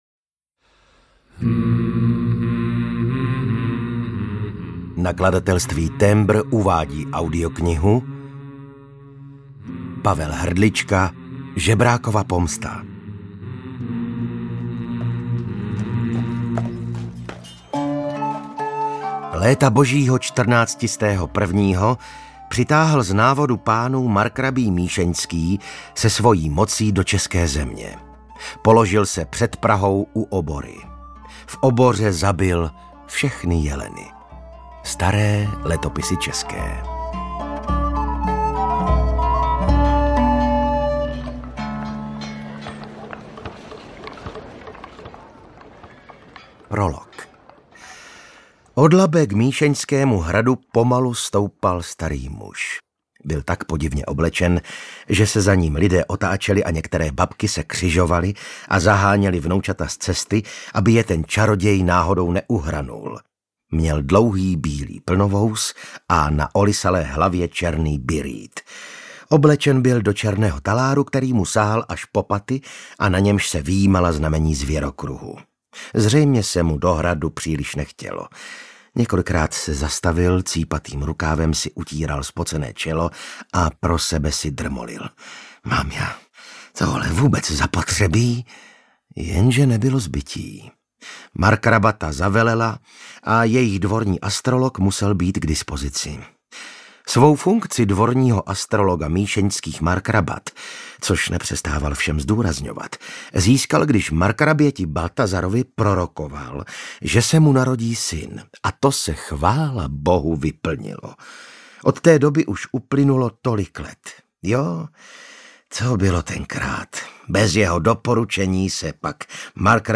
Žebrákova pomsta audiokniha
Ukázka z knihy
• InterpretVasil Fridrich